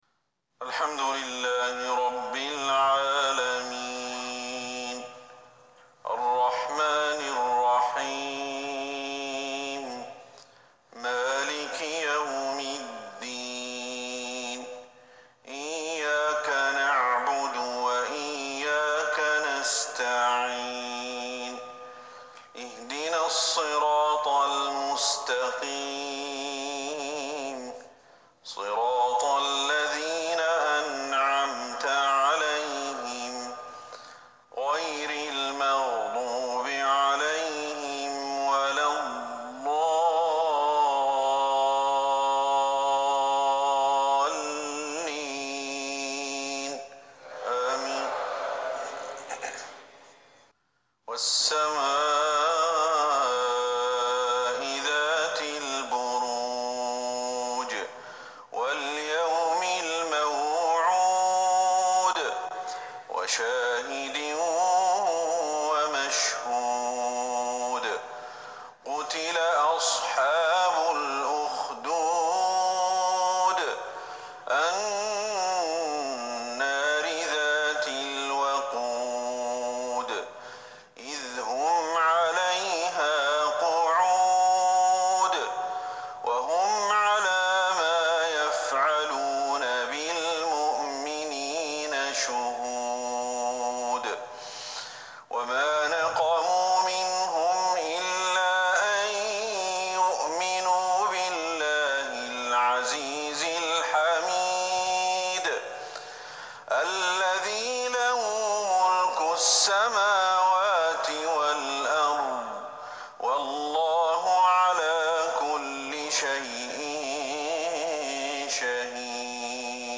صلاة المغرب ١٧ جمادى الاخرة ١٤٤١ سورة البروج